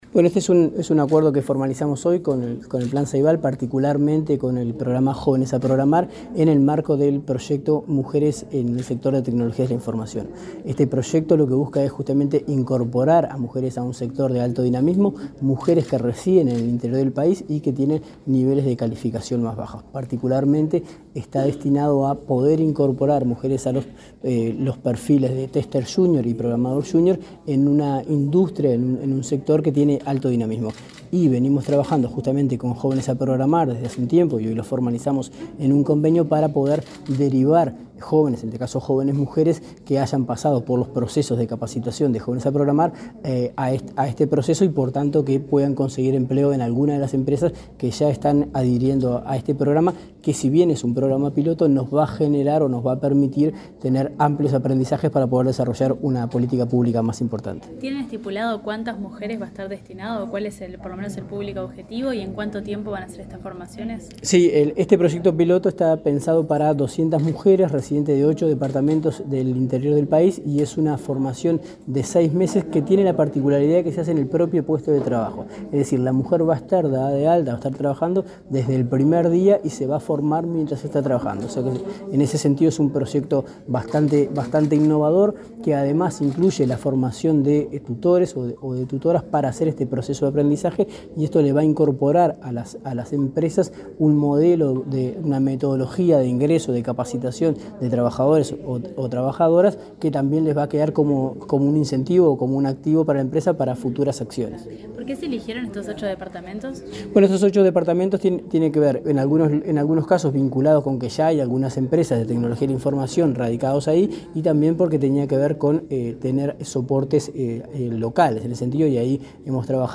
El director nacional de Empleo, Daniel Pérez, dialogó con la prensa luego de firmar un acuerdo de cooperación con representantes de Ceibal.